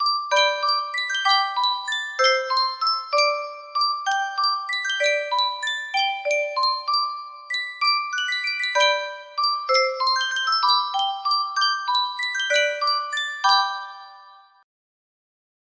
Yunsheng Custom Tune Music Box - Amazing Grace music box melody
Full range 60